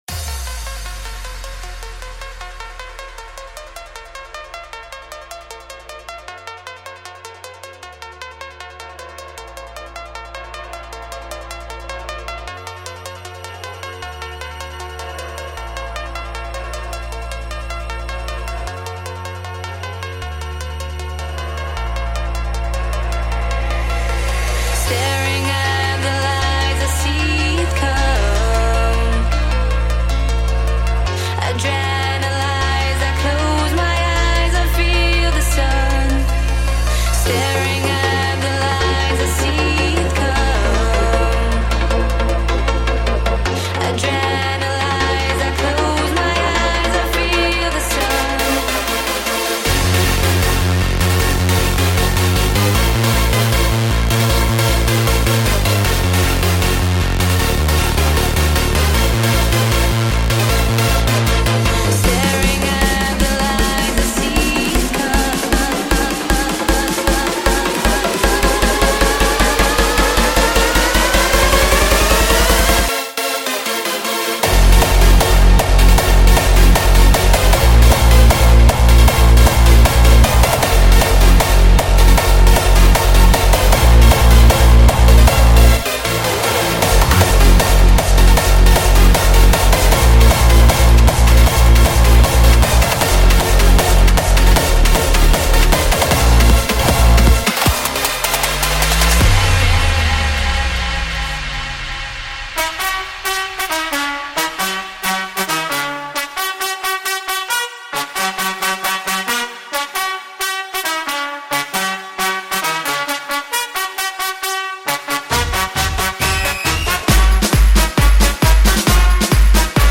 Genre: Hardstyle